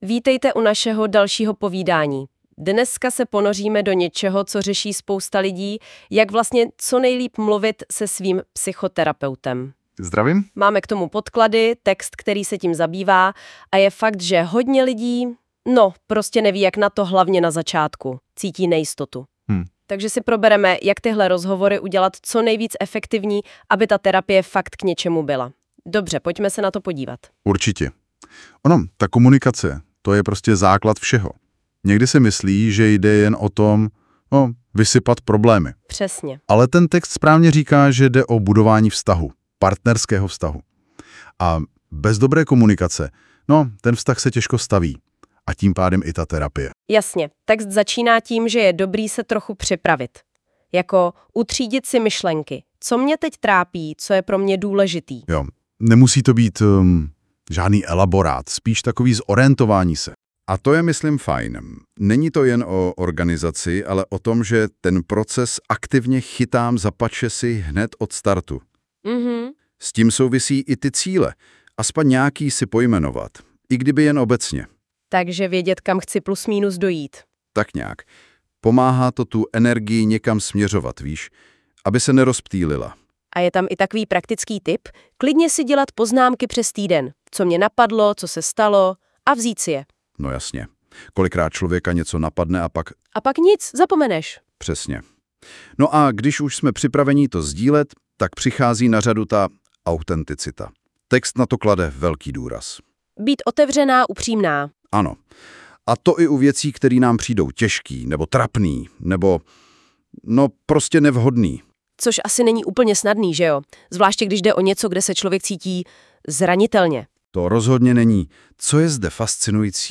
Audioverze vytvořená na základě tohoto článku pomocí nástroje NotebookLM.